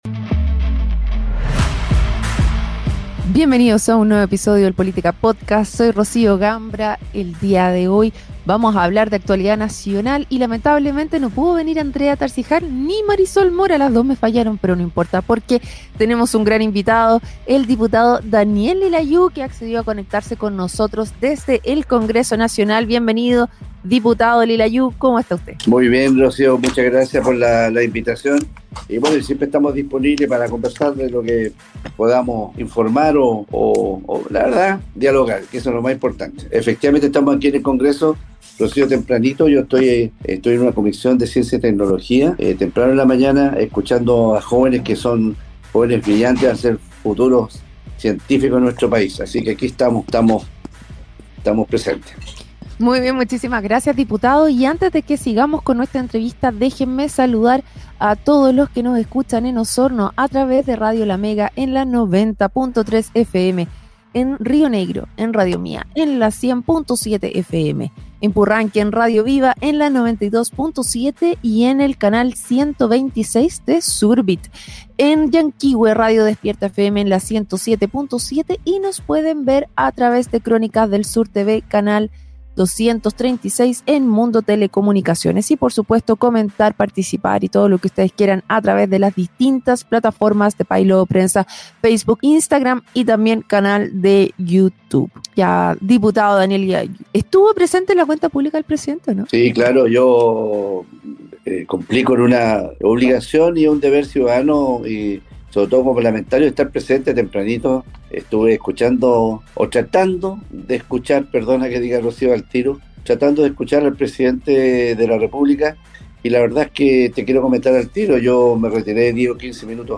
Desde el Congreso Nacional, en una entrevista concedida al programa "Política Podcast", el parlamentario abordó temas cruciales como la permisología, la estrategia nacional del litio, el financiamiento de las pensiones y la situación de la infraestructura hospitalaria, enfatizando la necesidad de un enfoque en el crecimiento económico y la resolución de las preocupaciones ciudadanas como la salud, la economía y la delincuencia.